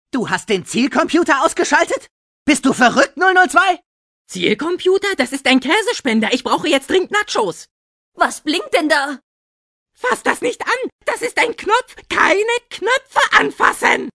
To guarantee the highest possible continuity with the popular TV series, the original actors who provided the German voices for the series were also hired for the video game.